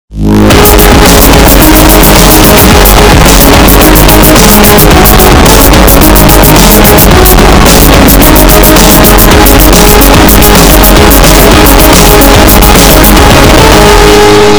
Games Soundboard